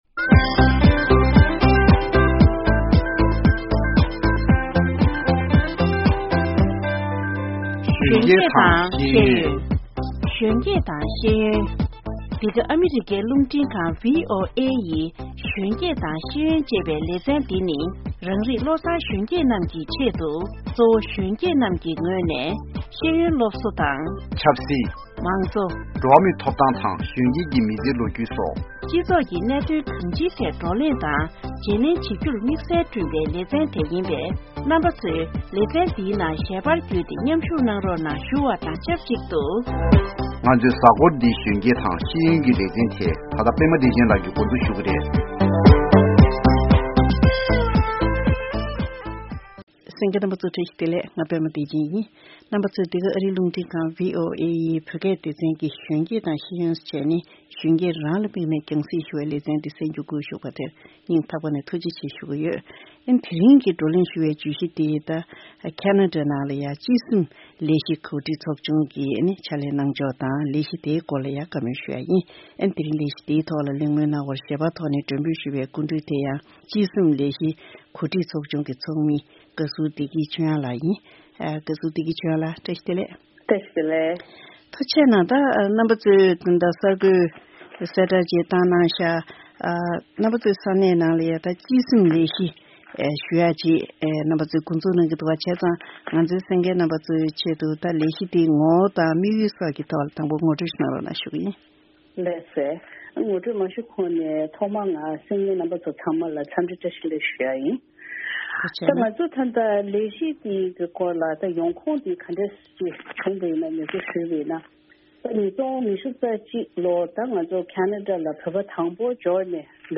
གཟའ་འཁོར་འདིའི་གཞོན་སྐྱེས་དང་ཤེས་ཡོན་ལས་རིམ་ནང་དུ་ཁེ་ན་ཌར་བོད་པ་གཞིས་ཆགས་པ་སླེབས་ནས་ལོ་༥༠་ཕྱིན་ཡོད་ཅིང་། ཁེ་ན་ཌར་ཐོག་མར་འབྱོར་བའི་བོད་པ་ཚོའི་མི་ཚེའི་ལོ་རྒྱུས་རྣམས་གཏན་འཇགས་འགྲེམ་སྟོན་ཆེད་དུ་བསྡུ་རུབ་ཀྱི་ལས་གཞི་སྟེ། སྤྱི་སེམས་ཞེས་པ་ཞིག་འགོ་འཛུགས་བྱུང་ཡོད་པའི་སྐོར་འབྲེལ་ཡོད་མི་སྣར་བཅར་འདྲི་ཞུས་པ་དེ་གསན་རོགས་གནང་།།